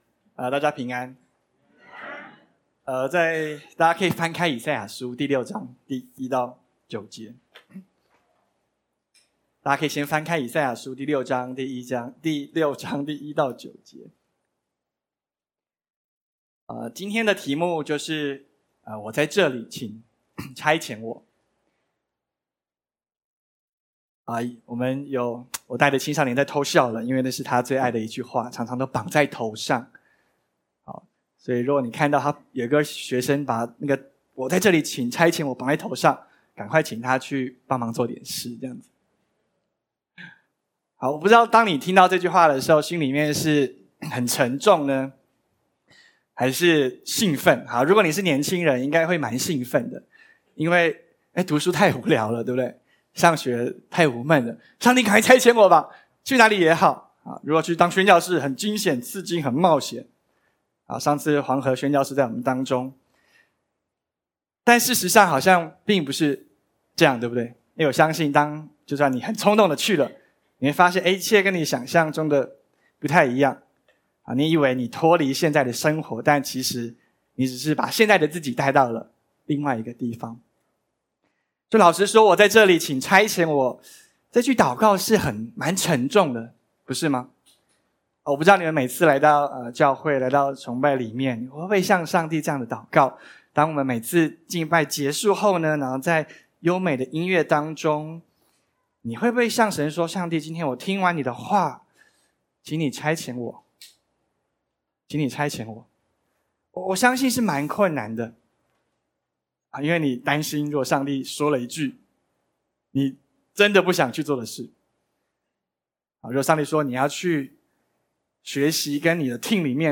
Posted in 主日信息